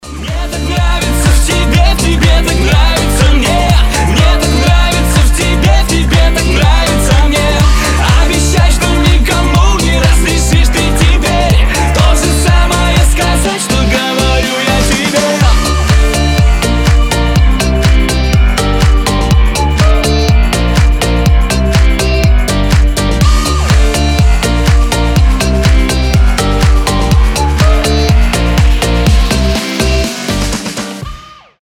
• Качество: 320, Stereo
поп
громкие
зажигательные